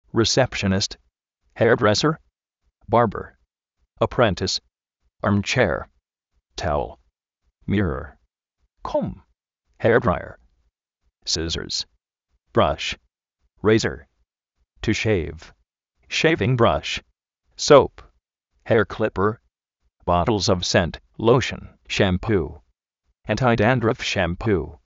recépshonist
jéar-dréser
bárber
apréntis